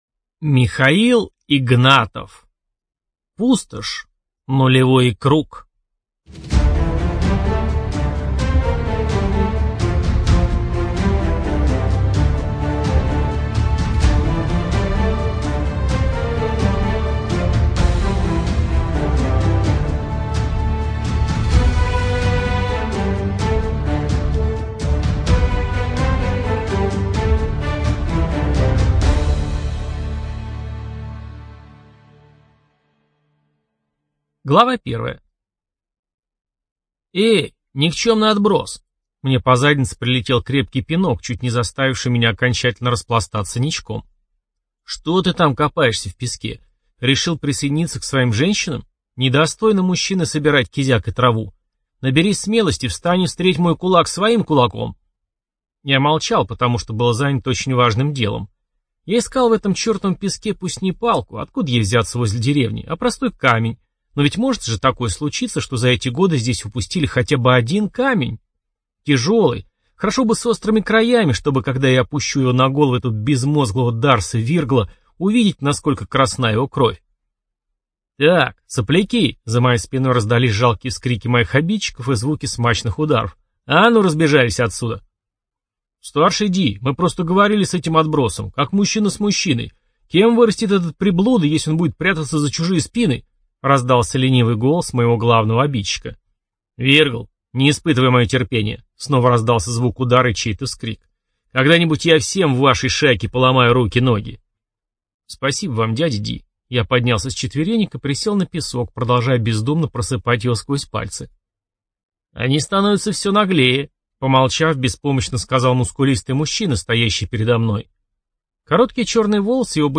ЖанрФэнтези